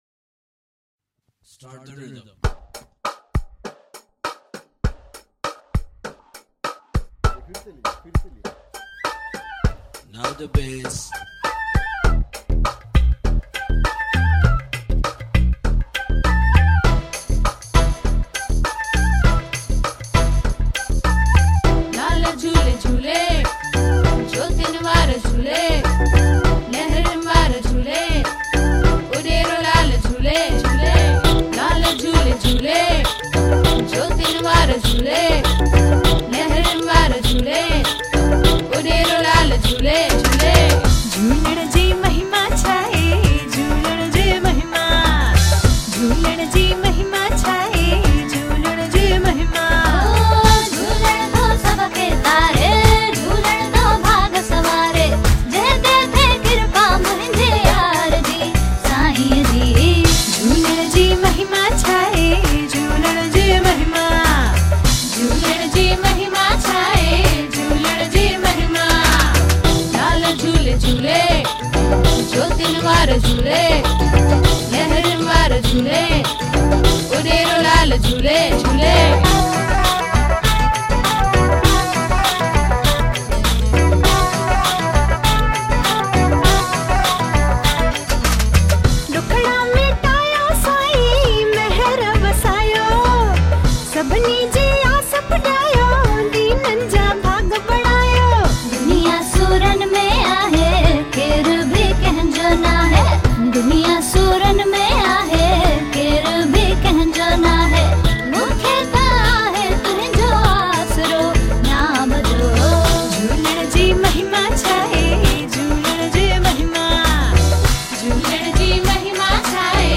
Sindhi Devotional songs